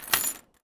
R - Foley 257.wav